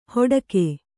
♪ hoḍake